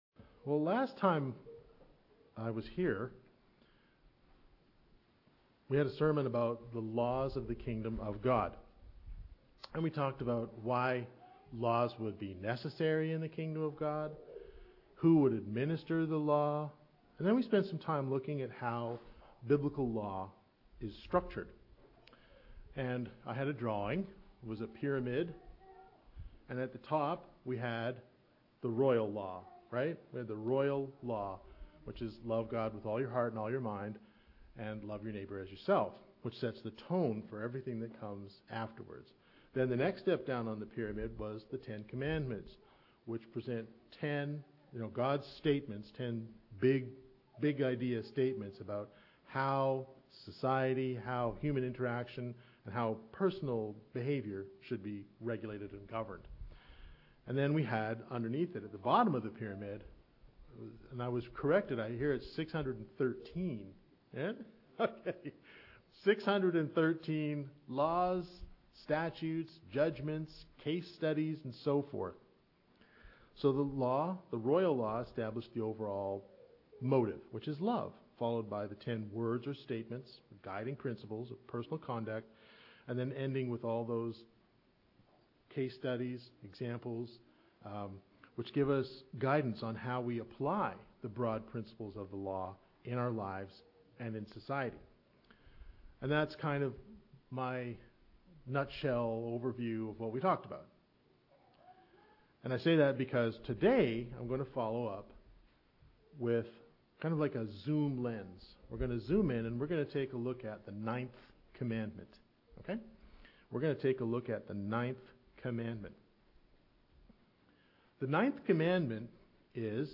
In last week’s sermon we looked at the place of Law in the KOG… why it would it even be necessary, who would administer that law… then we spent some time looking at how biblical law is structured… with the royal law at the top of the pyramid, then the commandments, then the statures and judgments.